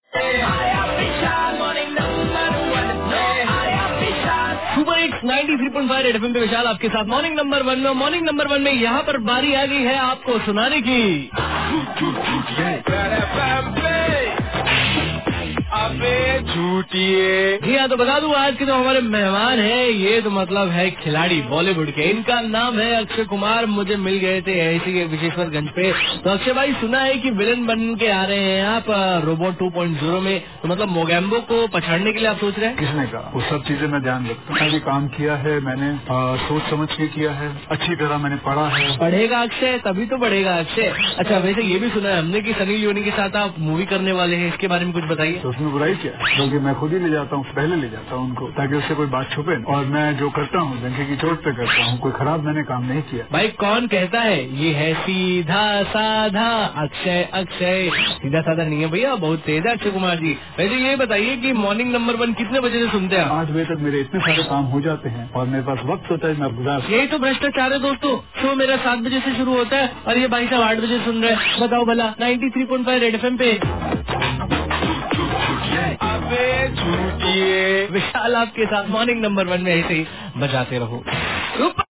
interviewed Akshay kumar